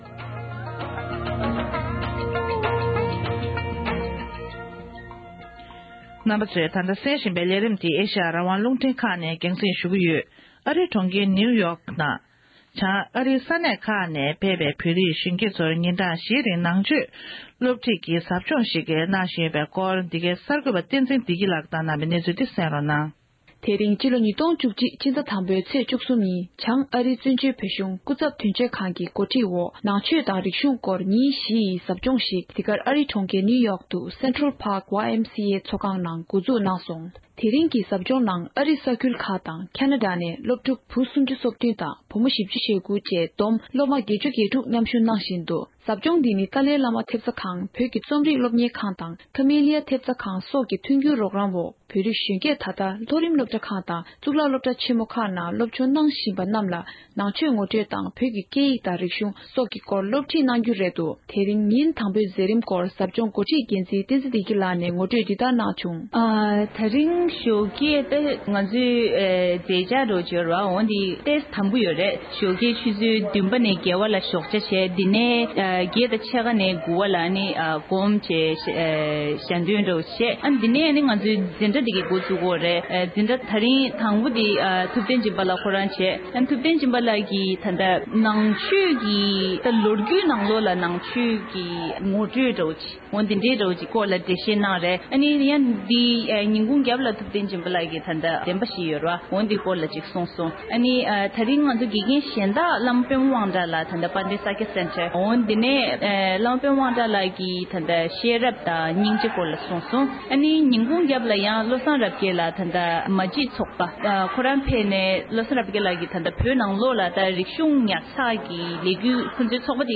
ས་གནས་ས་ཐོག་ནས་བཏང་འབྱོར་བྱུང་བའི་གནས་ཚུལ་ཞིག
གསར་འགྱུར